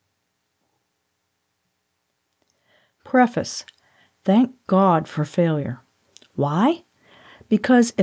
Gets rid of rumble which could screw up the other tools.
-71.9 dB Noise of room tone